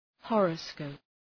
Προφορά
{‘hɔ:rə,skəʋp}